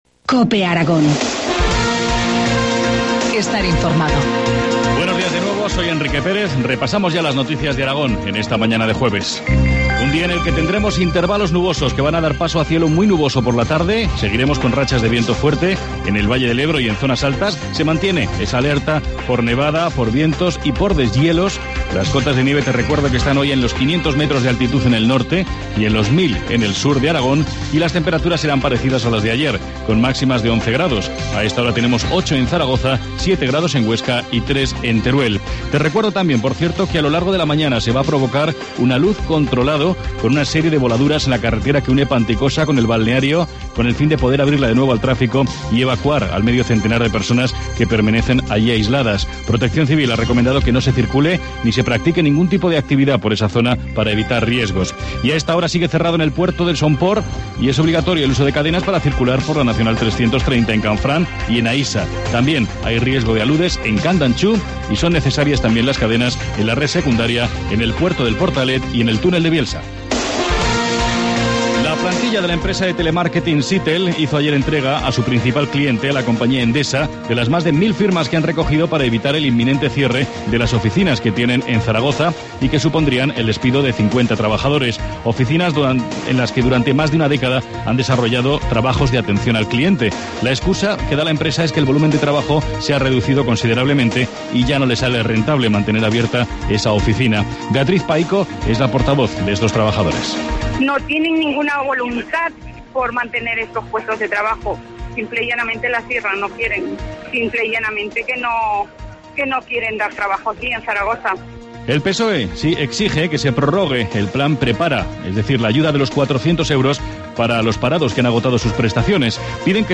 Informativo matinal, jueves 17 de enero, 8.25 horas